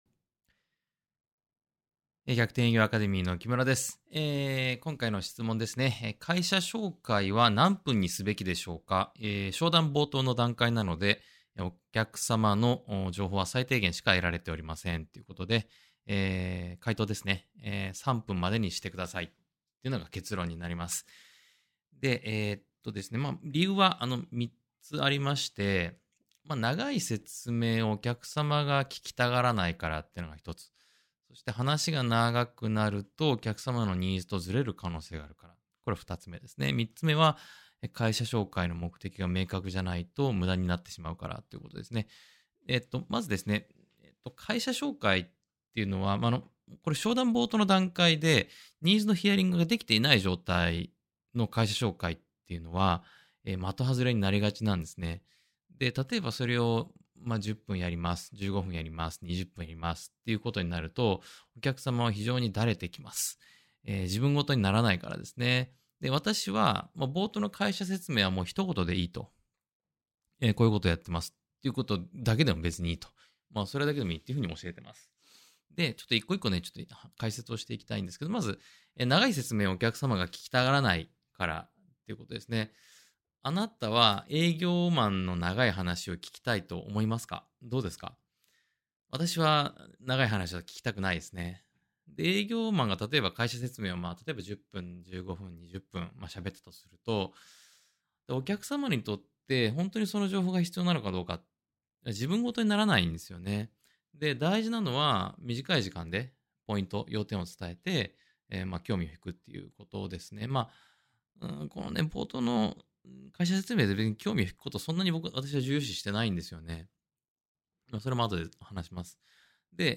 音声回答（︙をクリック→ダウンロード）